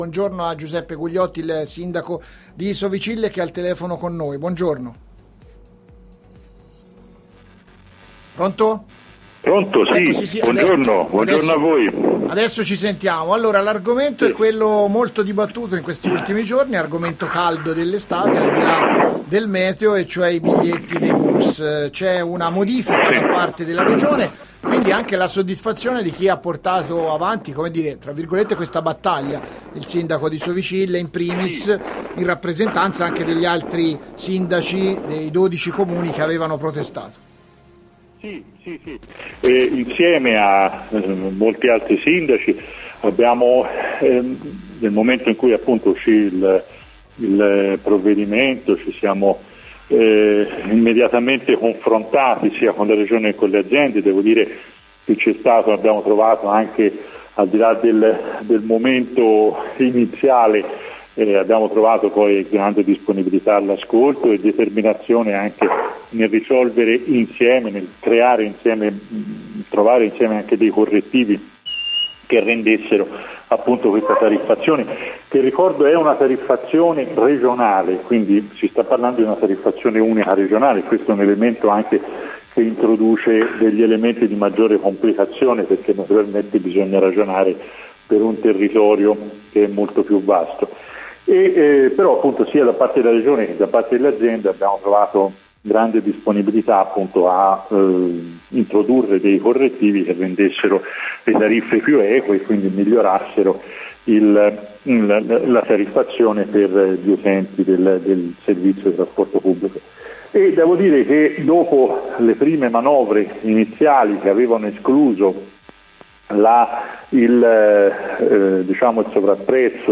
Diverse novità con sconti e promozioni, un carnet da 4 biglietti extraurbani a prezzo ridotto, l’abbonamento studenti da 10 mesi, un’offerta per i lavoratori pendolari. Ai nostri microfoni il sindaco di Sovicille, Giuseppe Gugliotti, portavoce dei sindaci del territorio che hanno portato avanti la protesta sul rincaro dei biglietti.